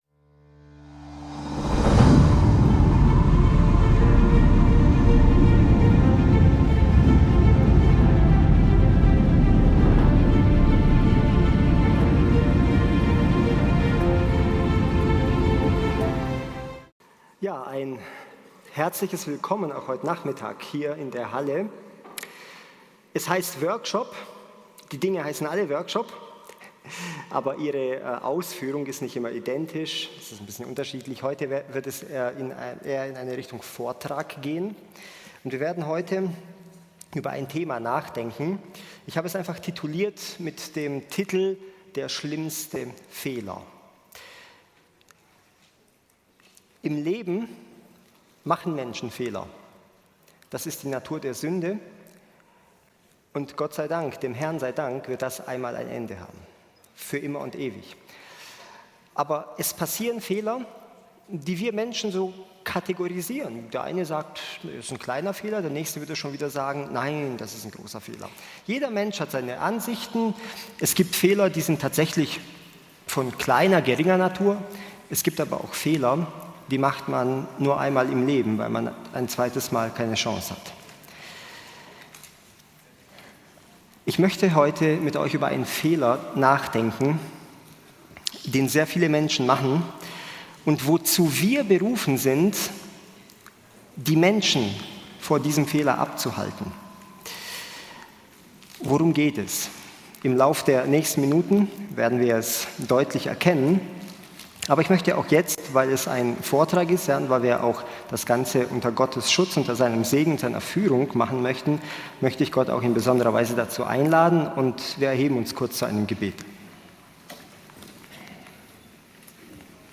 In einem fesselnden Vortrag wird der schlimmste Fehler der Menschen beleuchtet: das Abweichen vom Lebensweg zu Gott. Der Redner erläutert, wie viele nach Vergebung zurück in die Welt abdriften, ohne die Bedeutung des Kreuzes zu erfassen. Anhand biblischer und persönlicher Beispiele wird klargestellt, dass wahre Erlösung und Heiligung täglich neu erfahren werden müssen.